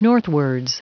Prononciation du mot : northwards